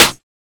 SNARE 17.wav